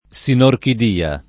sinorchidia [ S inorkid & a ] s. f. (med.)